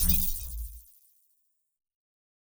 Deep HiTech UI Sound 2.wav